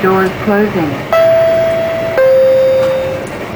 Almost Every 7k Announcement
The ones marked "Breda" were used on the Rohr and non-rehabbed Breda cars, i.e. the 1000 through 4000-Series, and were rolled out to those cars around 1996.